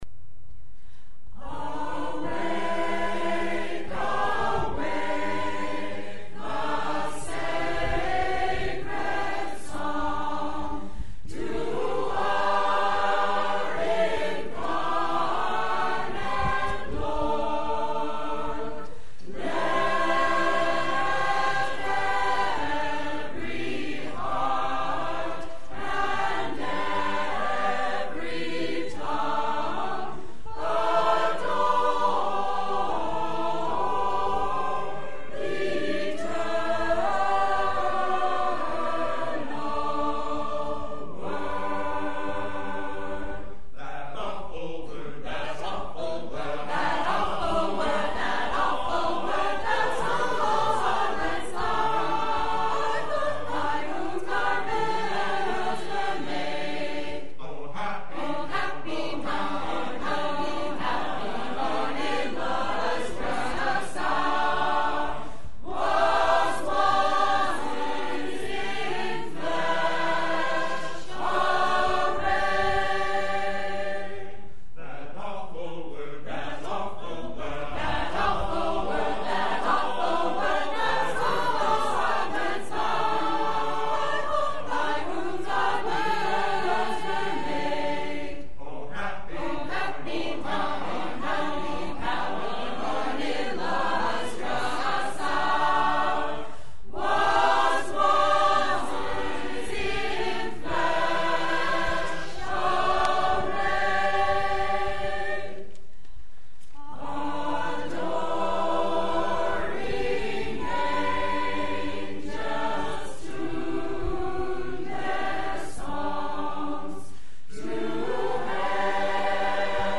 Here're some a "Real Media" and MP3 clips from the concert at Saxon's River